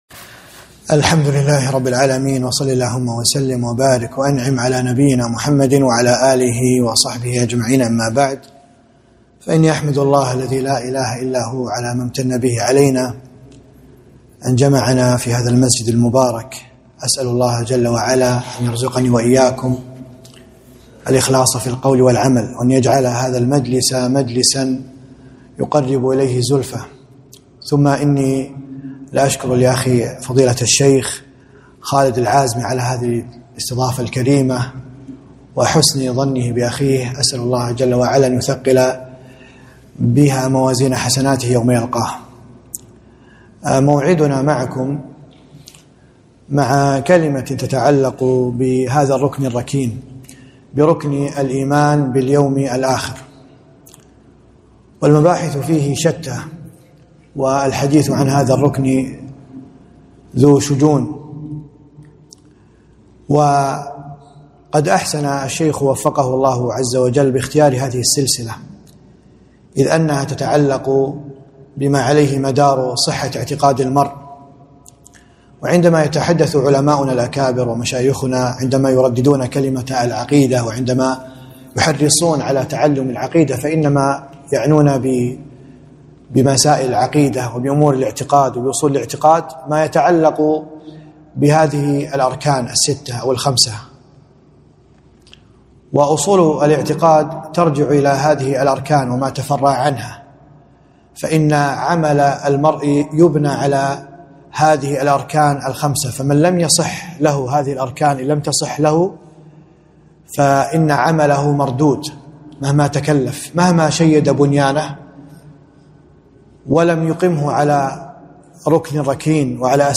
محاضرة - الإيمان باليوم الآخر